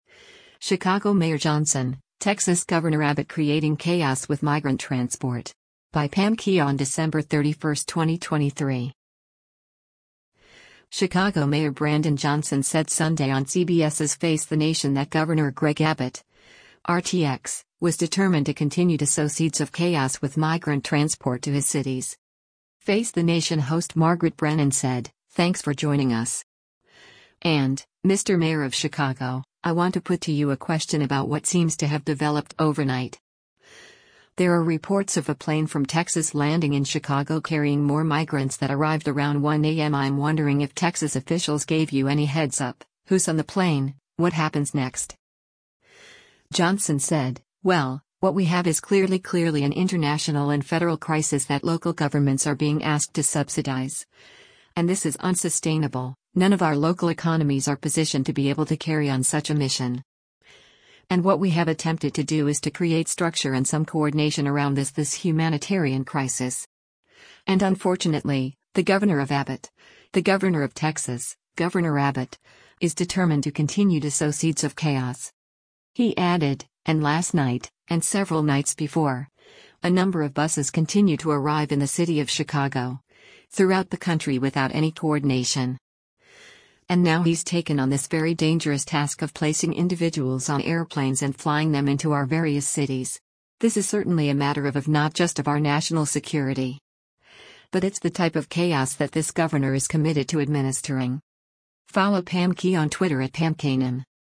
Chicago Mayor Brandon Johnson said Sunday on CBS’s “Face the Nation” that Gov. Greg Abbott (R-TX) was “determined to continue to sow seeds of chaos” with migrant transport to his cities.